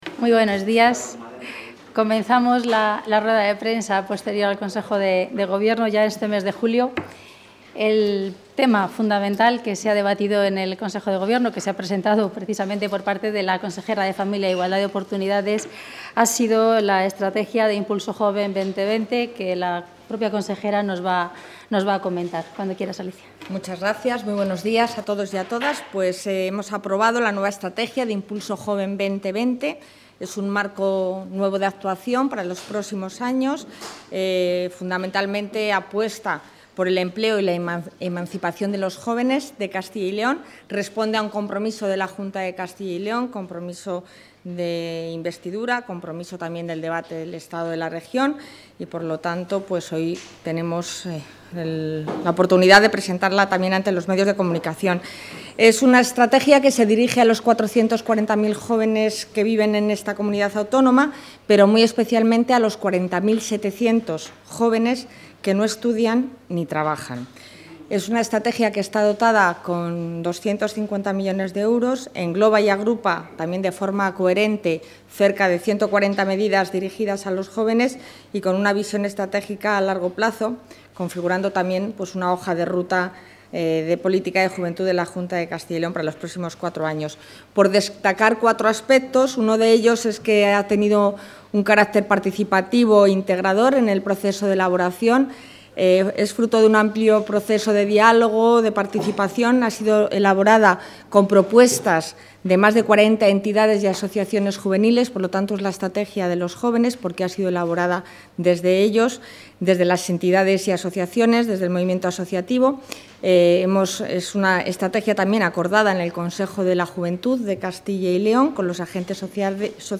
Audio rueda de prensa posterior al Consejo de Gobierno.